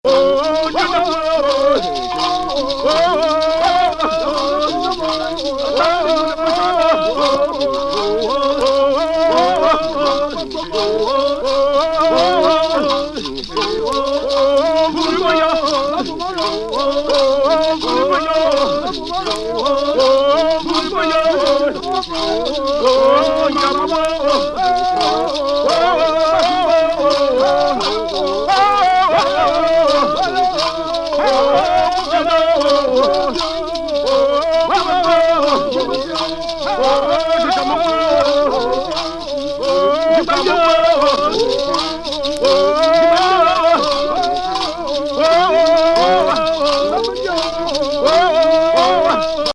カメルーン西部州バムーン王国のチャントをフィールド・レコードした一枚!